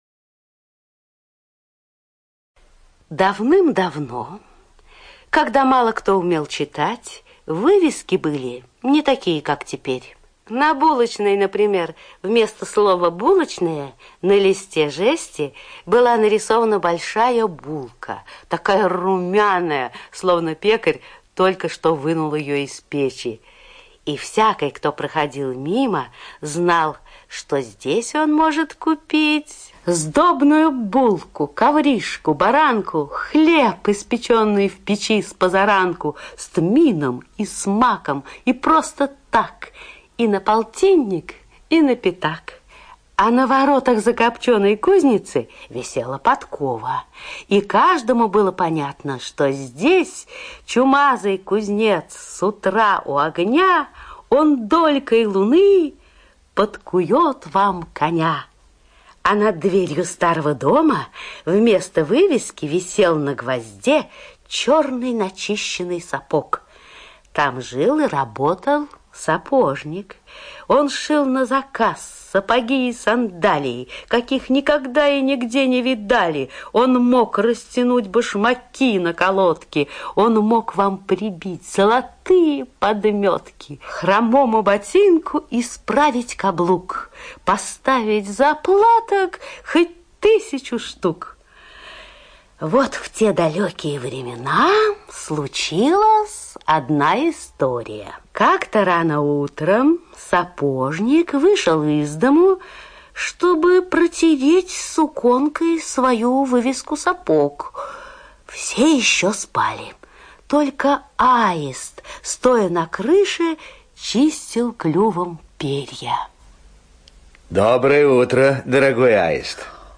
ЖанрСказки, Детский радиоспектакль